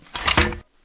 sounds / weapon / follyReload.ogg
follyReload.ogg